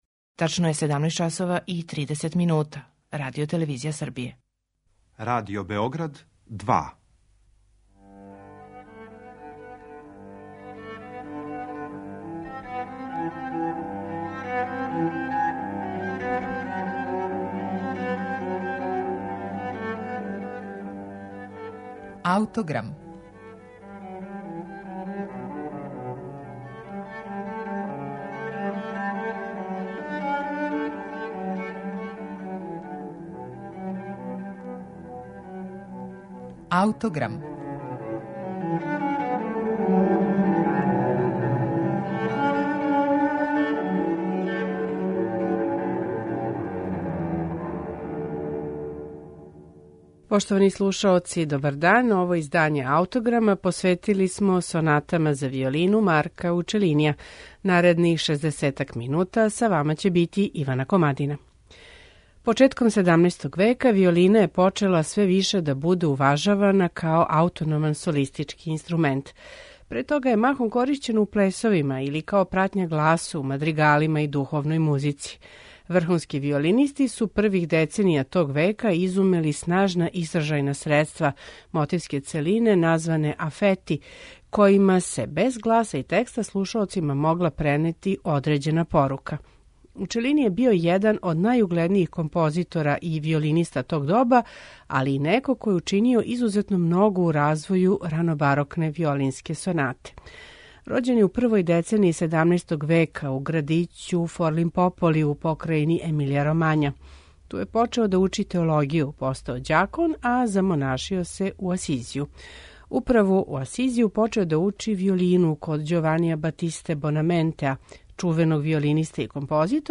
виолина
чембало и оргуље
лаута
виолончело